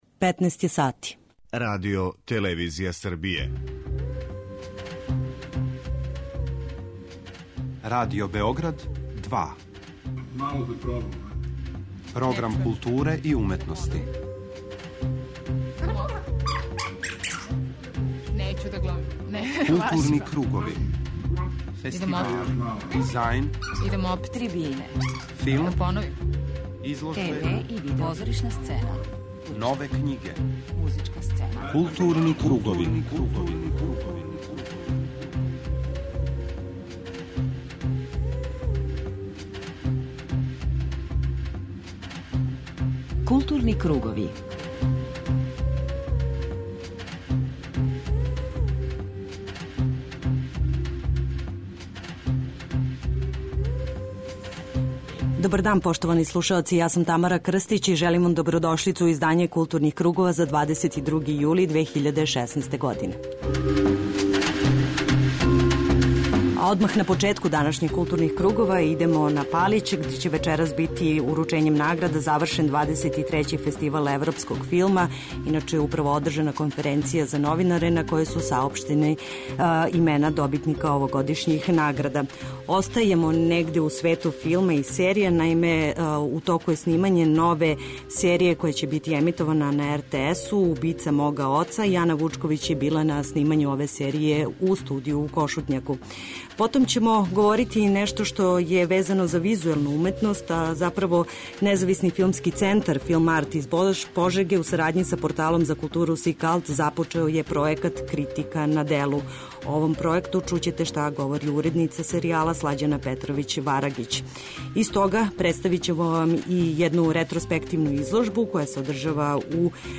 преузми : 41.20 MB Културни кругови Autor: Група аутора Централна културно-уметничка емисија Радио Београда 2.